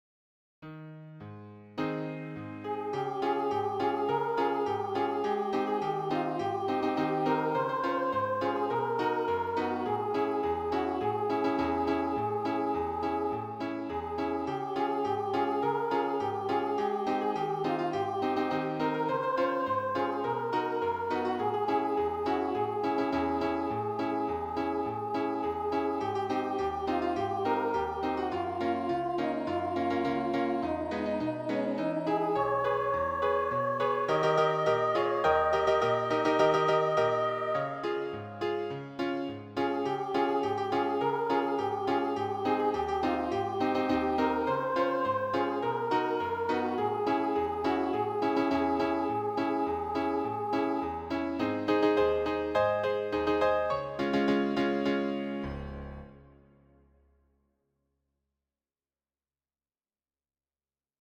There are also demo MP3 files of the three songs.